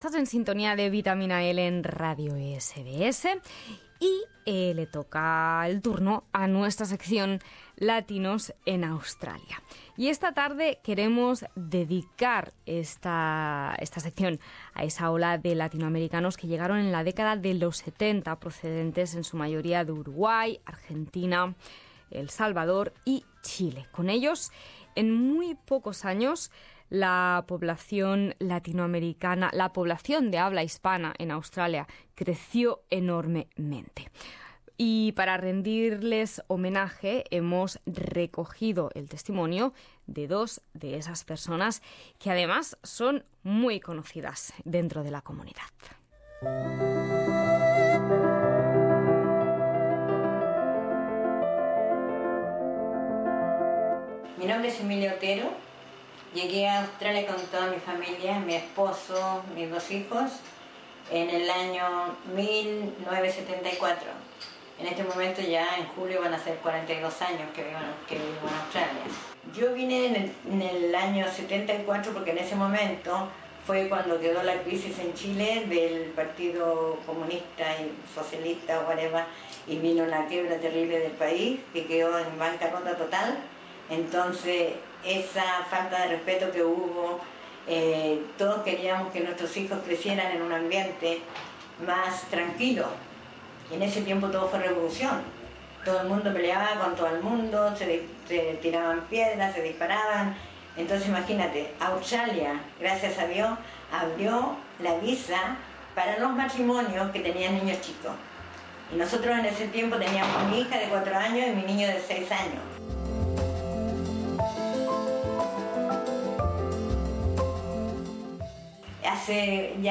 Latinos en Australia: Entrevista a dos personas de la gran ola de inmigrantes latinoamericanos de los 70.